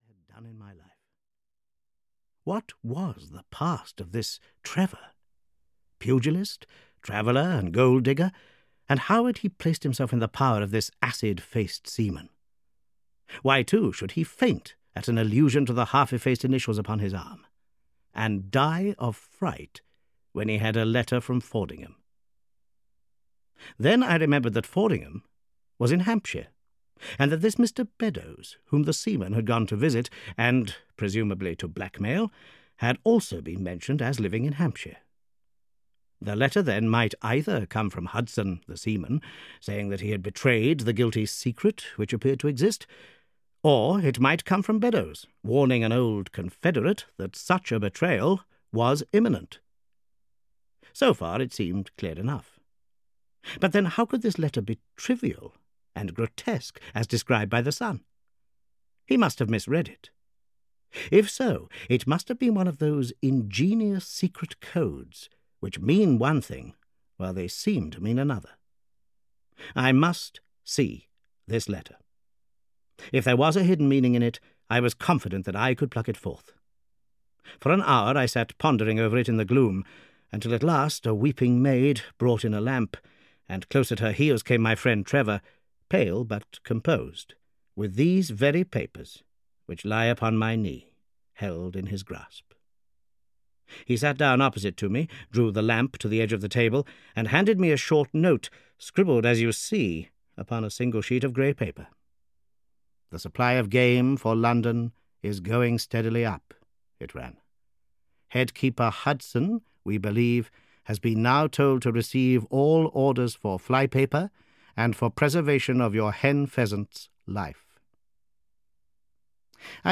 The Adventures of Sherlock Holmes VI (EN) audiokniha
Ukázka z knihy